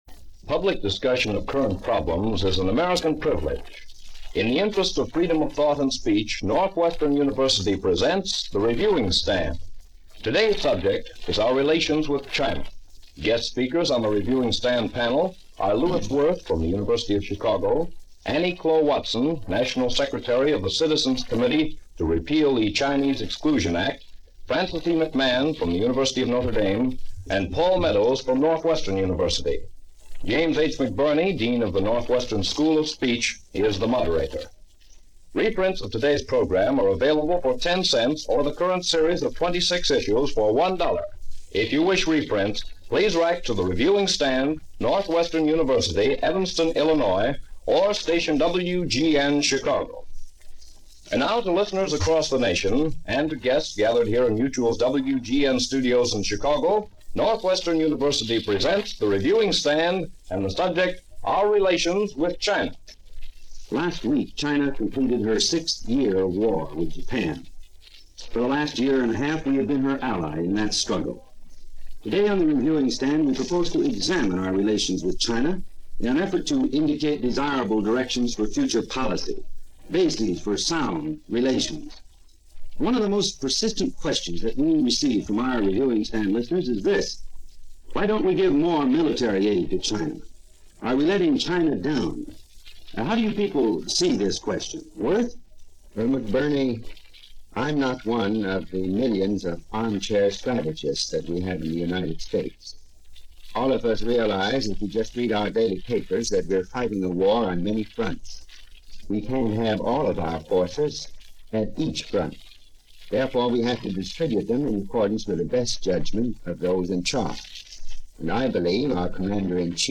The panel, during the course of the half-hour argues vigorously for and against raising the quotas to allow more Chinese in.